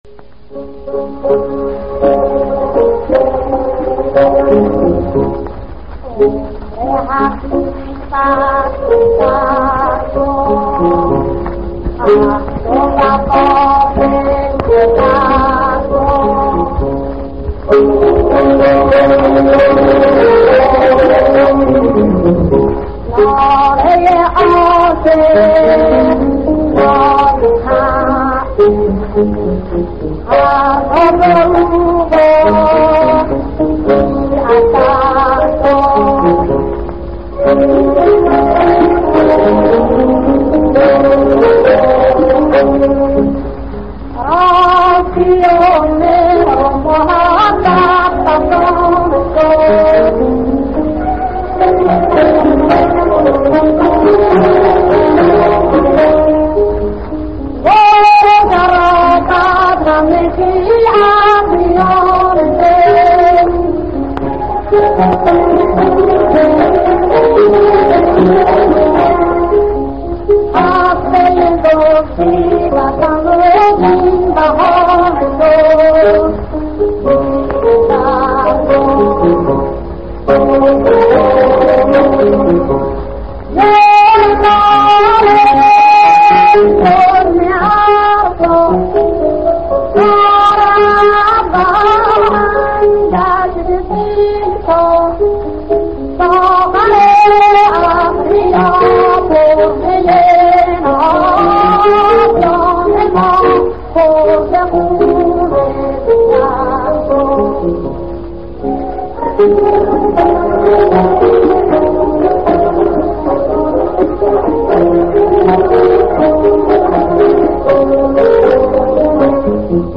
آواز . شاید بانو ایران الدوله یا سرکار جمال صفوی باشد